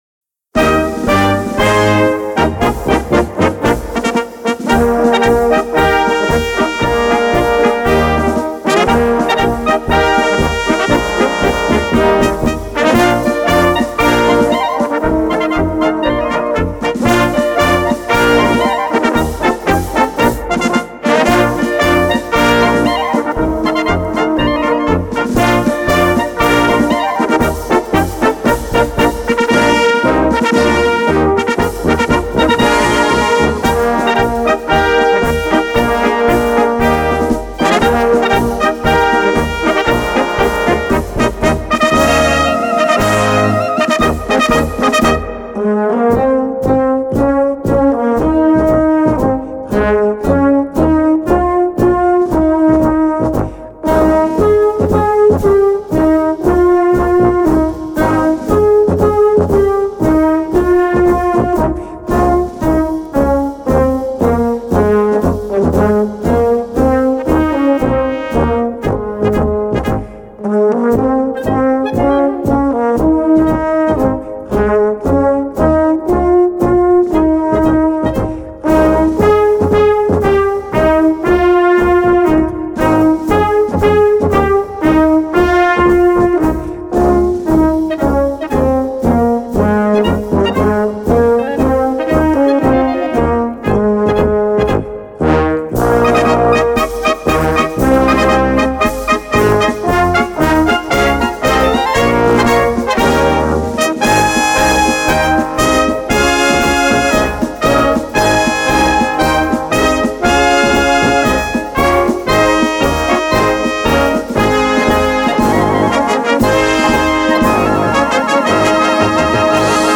Kategorie Blasorchester/HaFaBra
Unterkategorie Strassenmarsch
Besetzung Ha (Blasorchester)